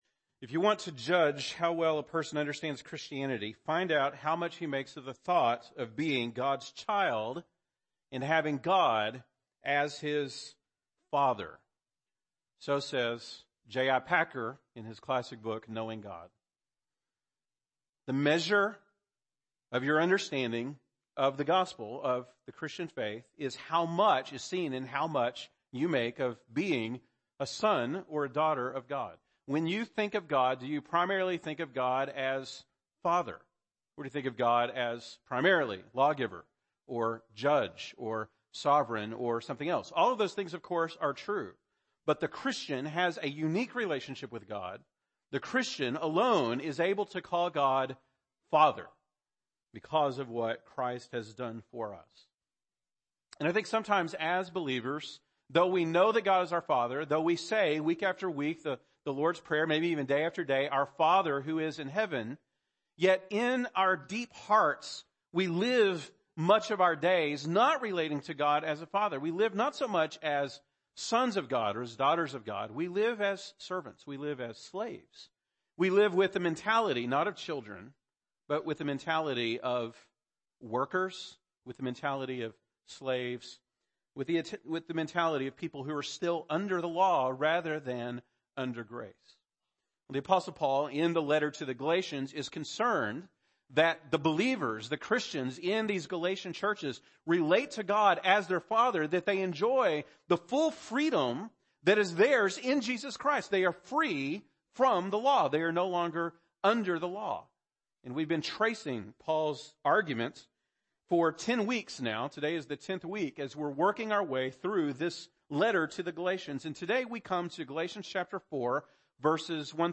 October 29, 2017 (Sunday Morning)